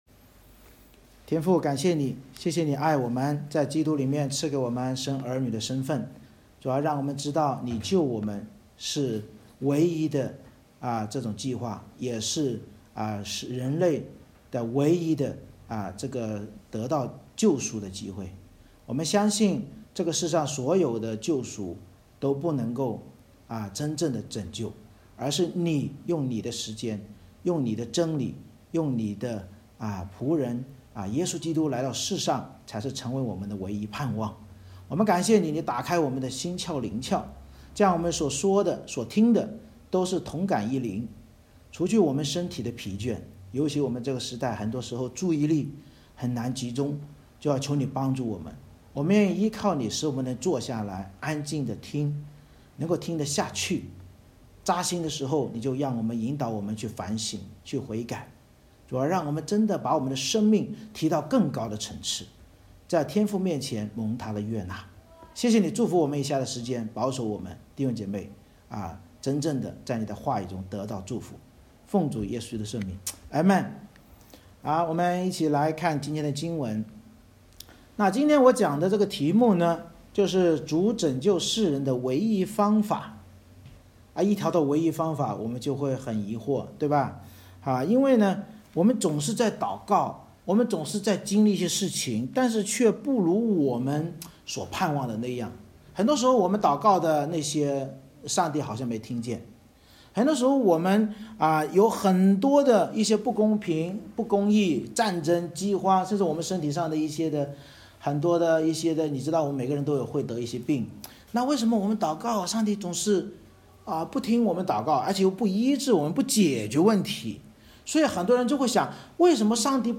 以赛亚书Isaiah59:1-21 Service Type: 主日崇拜 主耶和华藉先知以赛亚揭露所有人都没有公平公义，也无法自救，教导我们惟有主的救法即差遣救赎主耶稣来到世上，才能使人离弃罪恶并活出公平公义的见证。